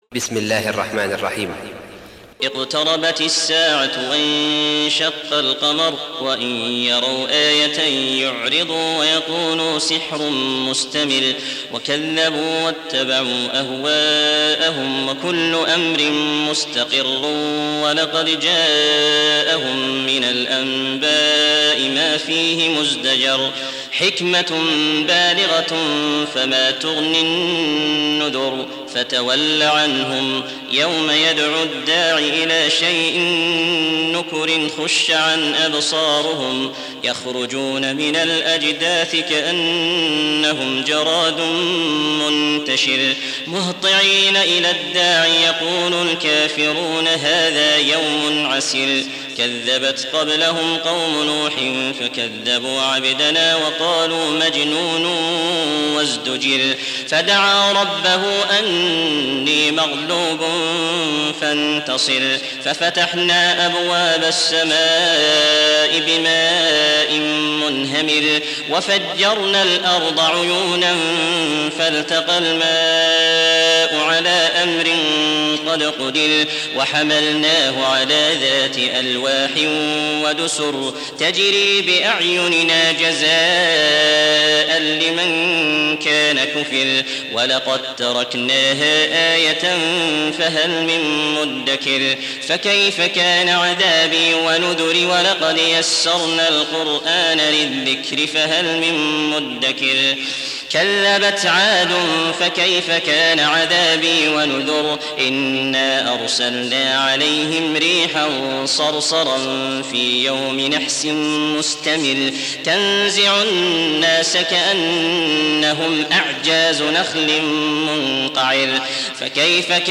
Tarteel Recitation
Surah Repeating تكرار السورة Download Surah حمّل السورة Reciting Murattalah Audio for 54. Surah Al-Qamar سورة القمر N.B *Surah Includes Al-Basmalah Reciters Sequents تتابع التلاوات Reciters Repeats تكرار التلاوات